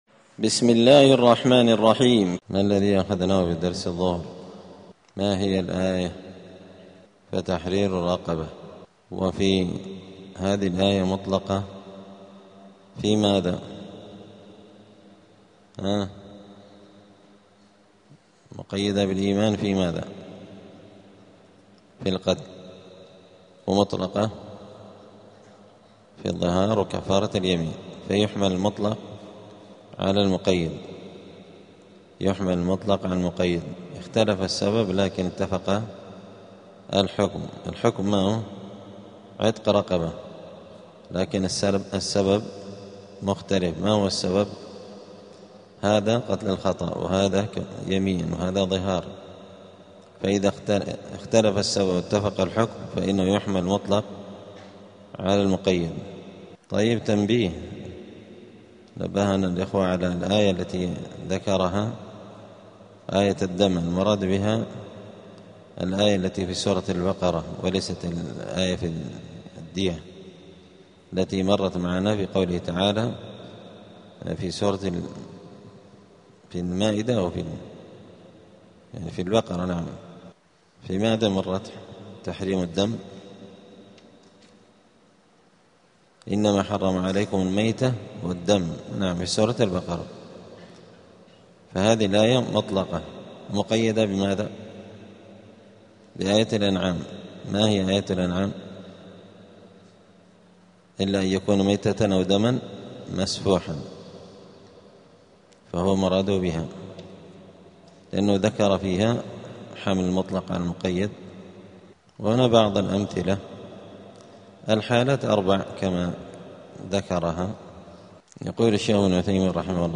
*الدرس السادس العشرون (26) {سورة النساء}.*